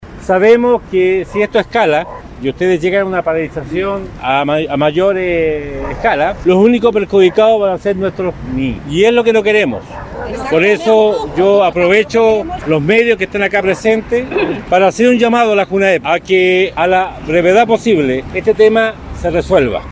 El alcalde de Osorno, Emeterio Carrillo, entregó su apoyo a las funcionarias a nombre del concejo, e informó que se oficiaría a Junaeb por la situación de las trabajadoras.